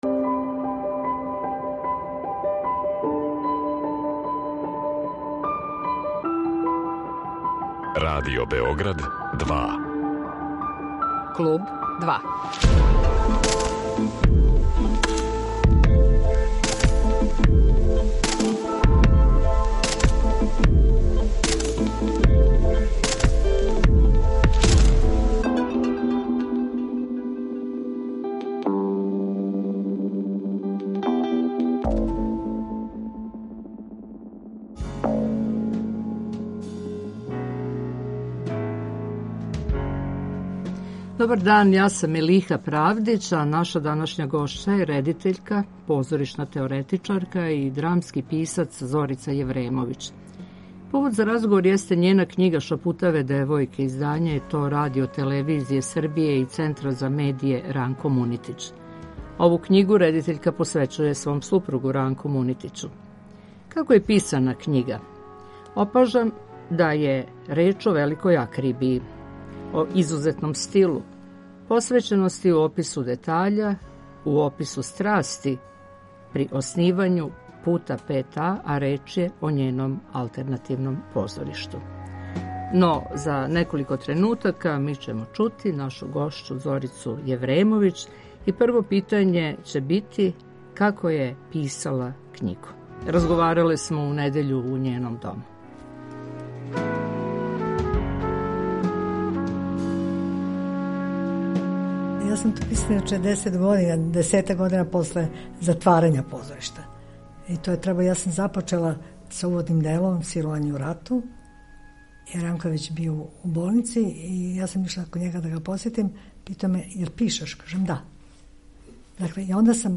У Клубу 2 емитујемо разговор